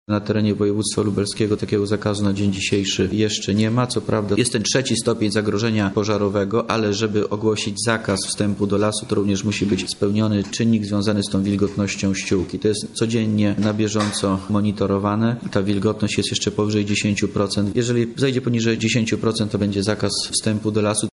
mówi wojewoda lubelski Wojciech Wilk